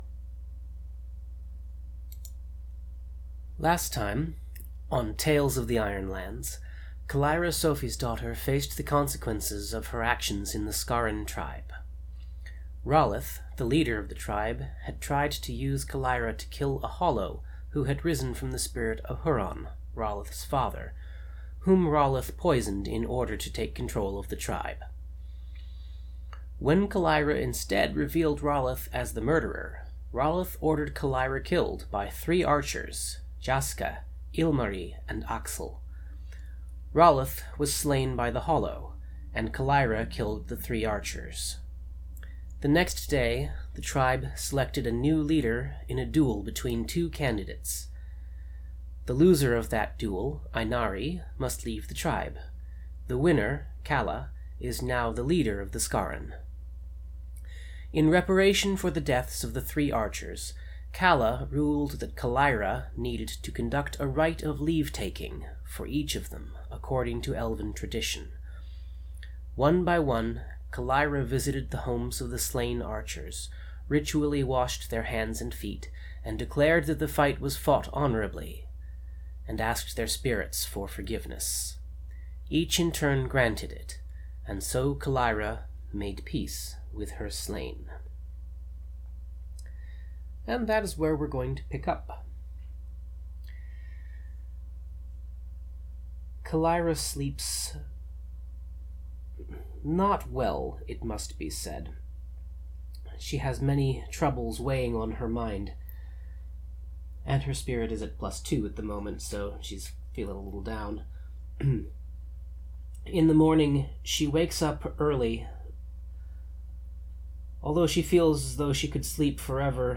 Note: This has been edited to remove uninteresting bits such as silence, throat-clearing and paper shuffling.